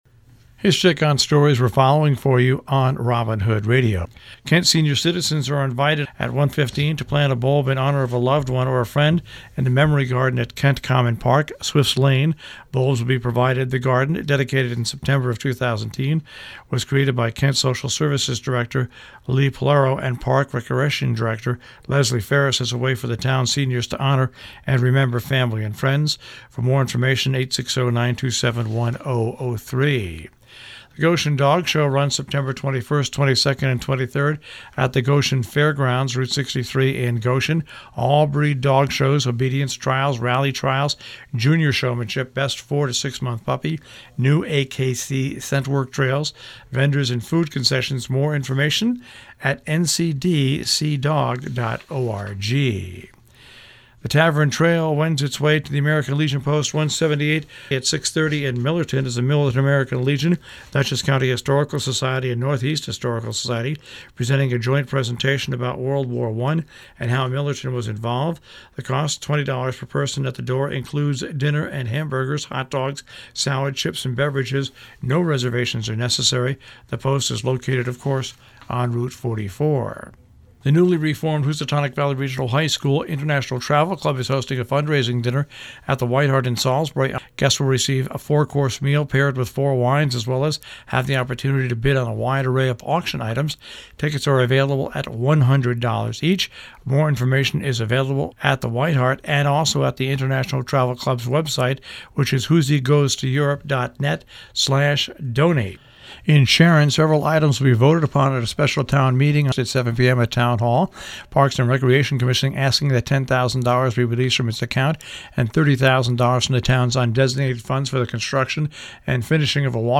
Robin Hood Radio is listener supported local public radio.